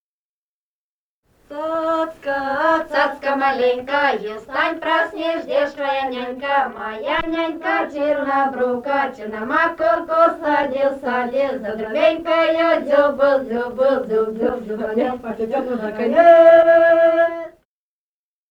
Этномузыкологические исследования и полевые материалы
«Цацка, цацка маленька» (плясовая на масленицу).
Румыния, с. Переправа, 1967 г. И0973-04